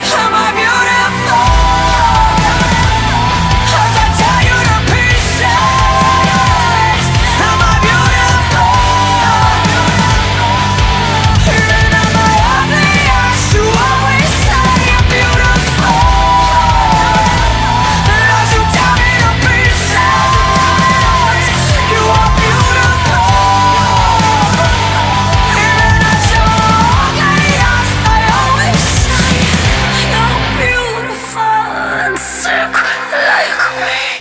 • Качество: 192, Stereo
Женский вокал это всегда красиво